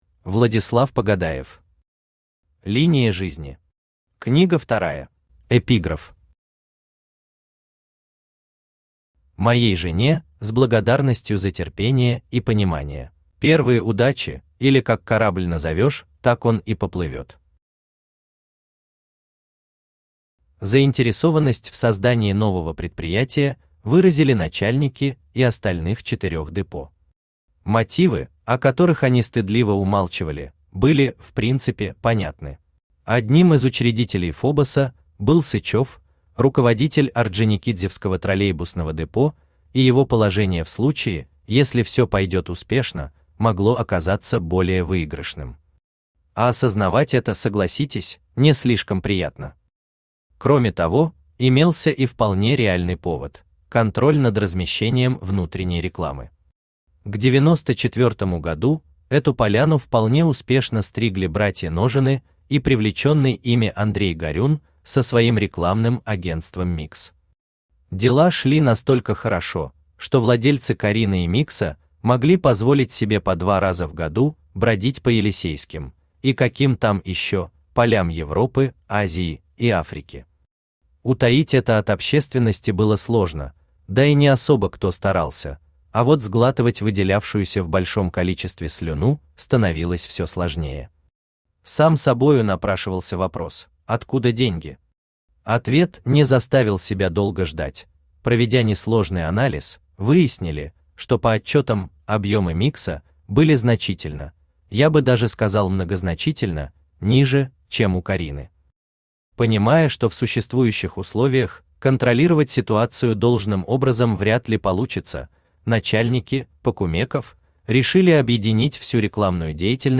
Аудиокнига Линия жизни. Книга вторая | Библиотека аудиокниг
Книга вторая Автор Владислав Михайлович Погадаев Читает аудиокнигу Авточтец ЛитРес.